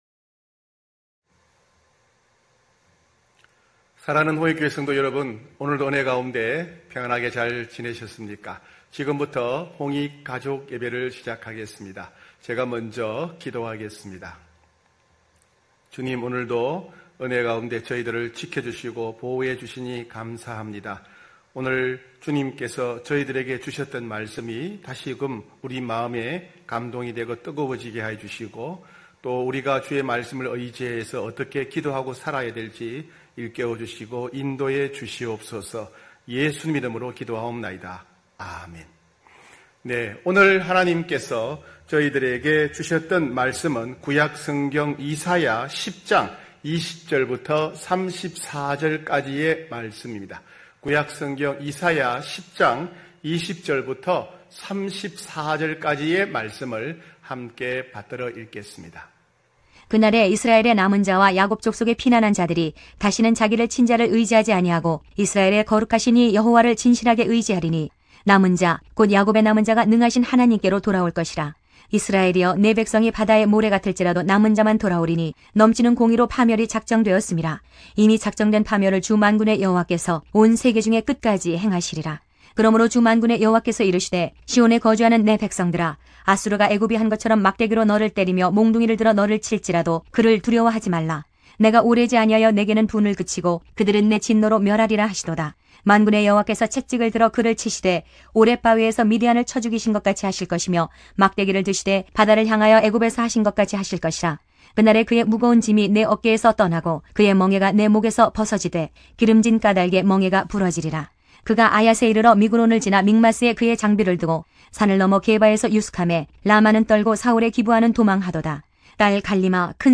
9시홍익가족예배(7월25일).mp3